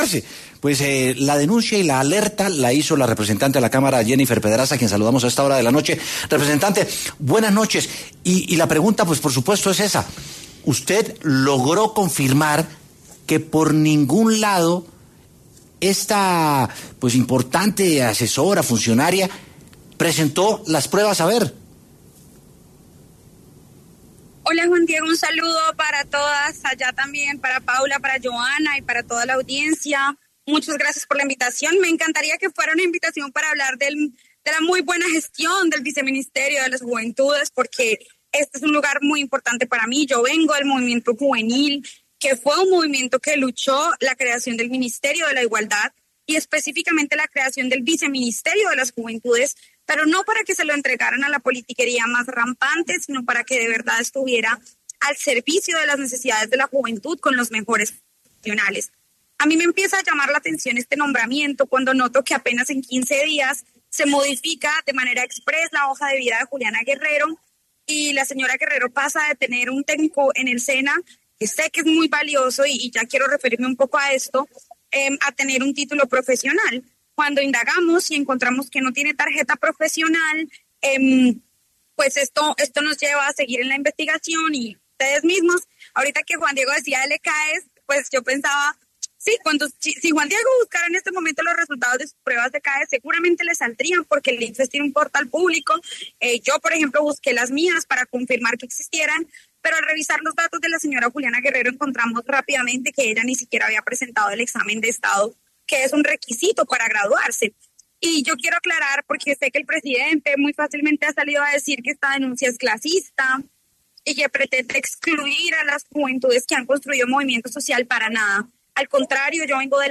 Jennifer Pedraza, representante a la Cámara, estuvo en W Sin Carreta para hablar sobre Juliana Guerrero, quien sería la próxima viceministra de la Juventud.